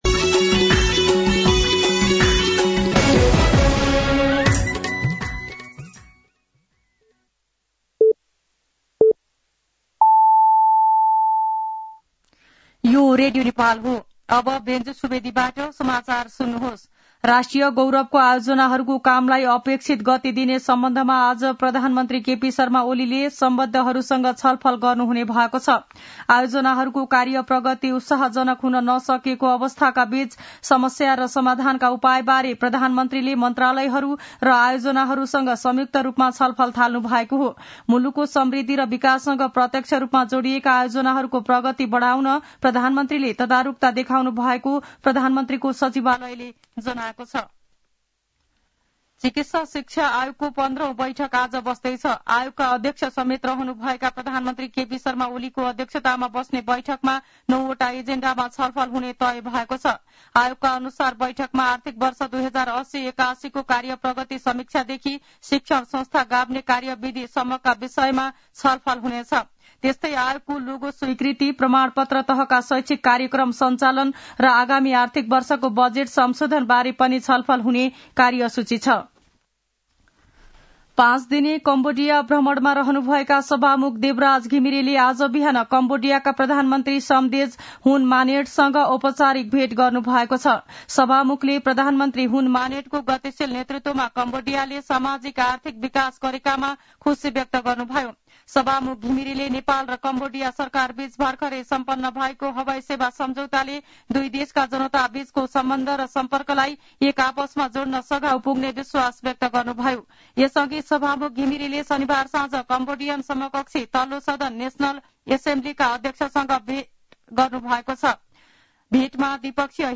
मध्यान्ह १२ बजेको नेपाली समाचार : १० मंसिर , २०८१
12-am-Nepali-News.mp3